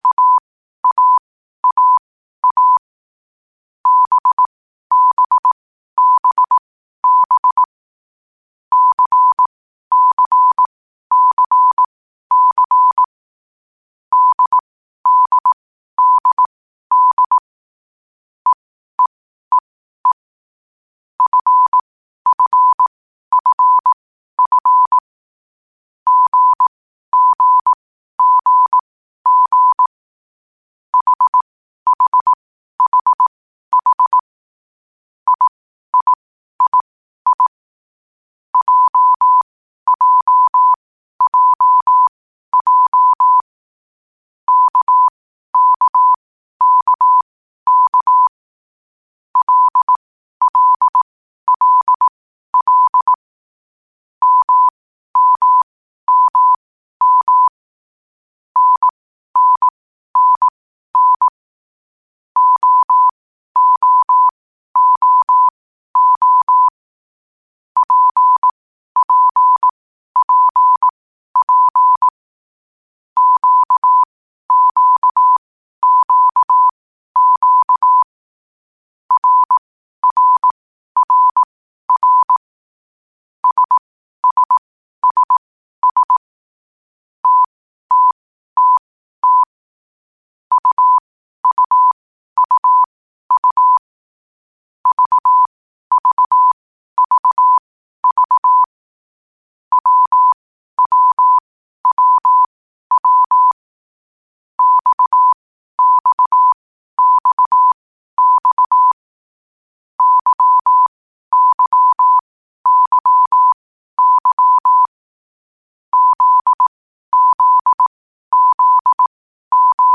Morsealphabet in langsamer Geschwindigkeit zum Hören. Dabei ist jeder Buchstabe vier Mal hintereinander aufgenommen worden, danach kommt direkt der nächste.
alphabet.mp3